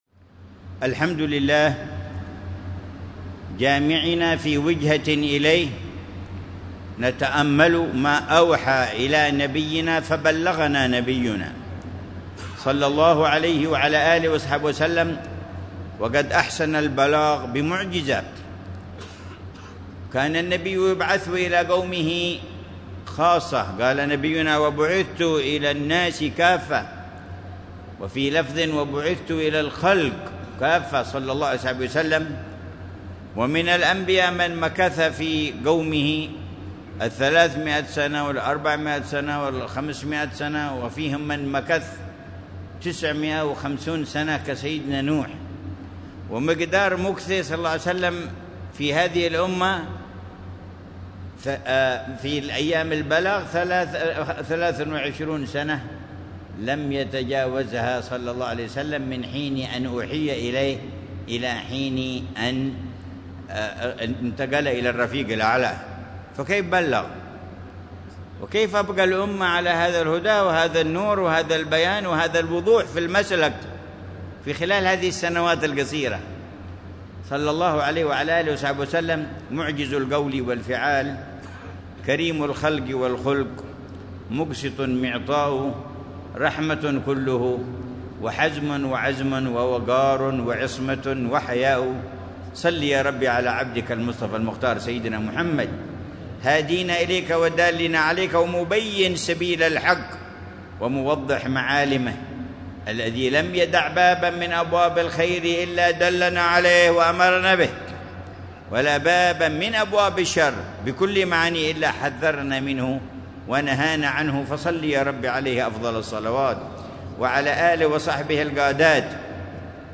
محاضرة العلامة الحبيب عمر بن محمد بن حفيظ في جامع منطقة صيف، بوادي دوعن، ليلة الإثنين 14 ربيع الثاني 1447هـ بعنوان: